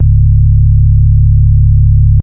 015_sustained_3_octave.wav